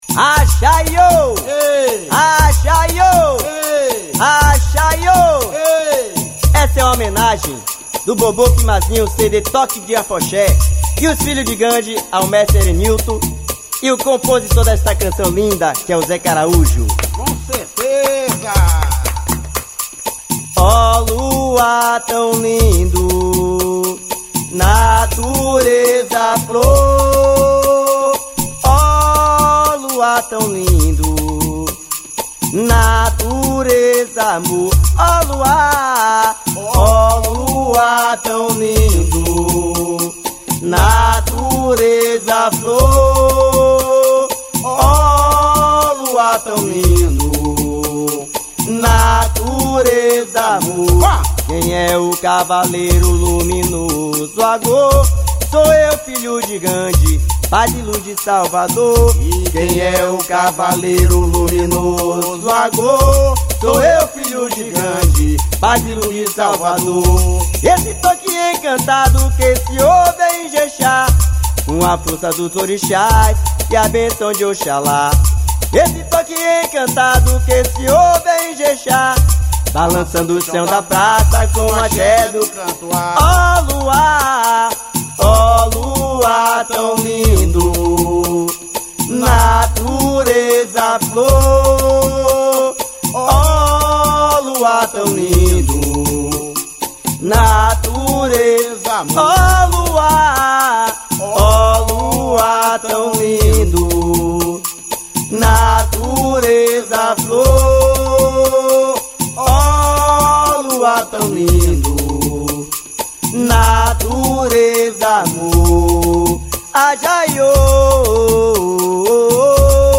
EstiloWorld Music